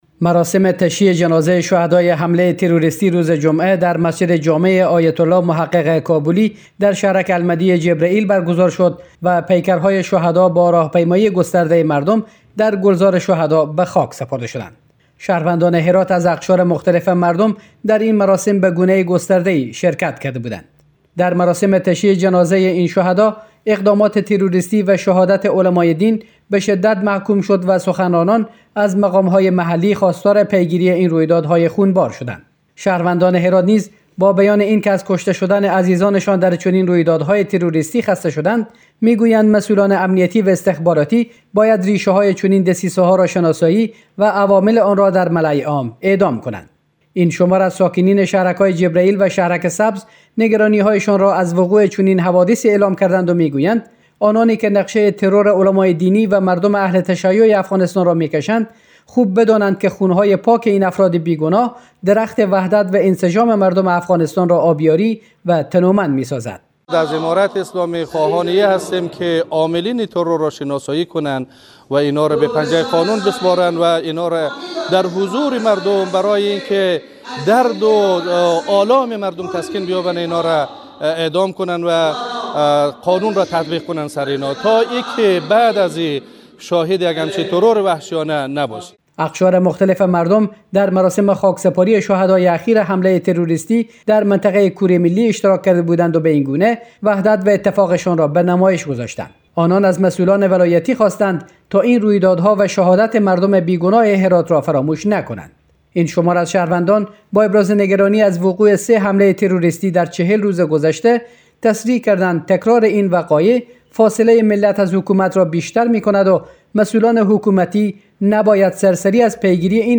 مراسم خاکسپاری پیکر شهدای حمله تروریستی روز جمعه هرات با حضور گسترده مردم ،در مسجد جامع آیت‌الله محقق کابلی(ره) در شهرک المهدی جبرئیل برگزار شد.